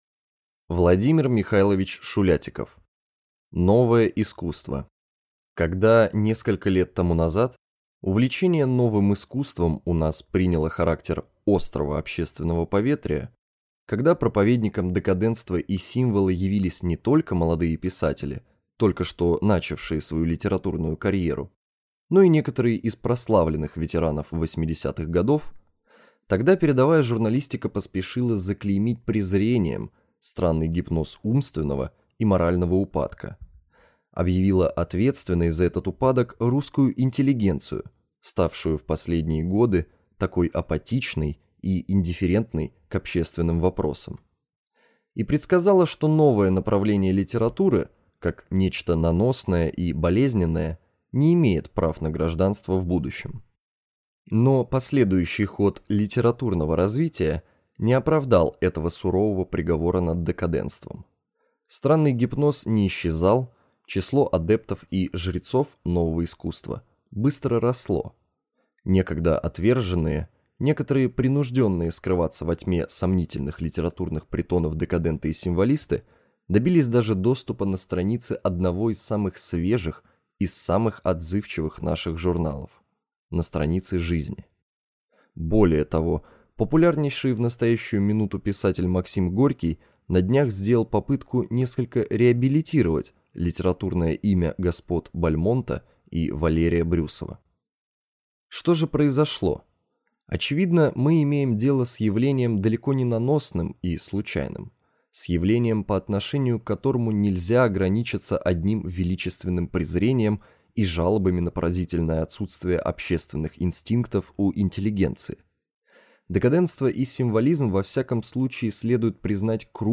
Аудиокнига «Новое искусство» | Библиотека аудиокниг